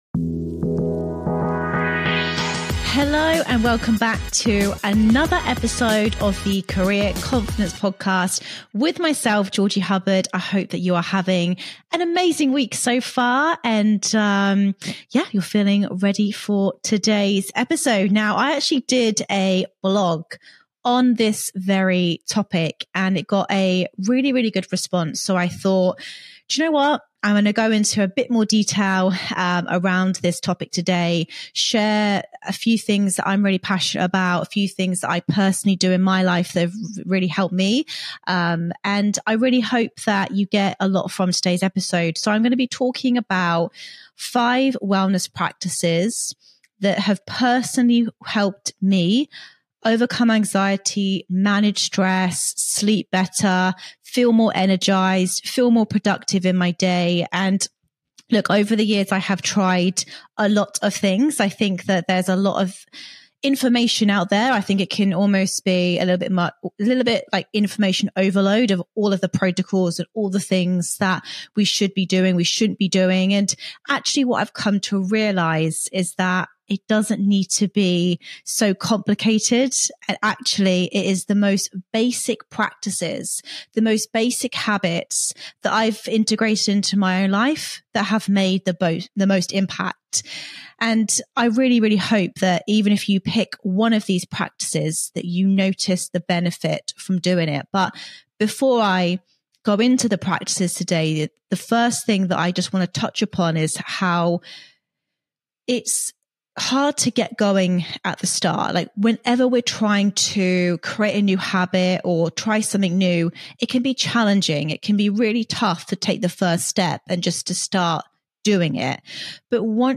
In today's solo episode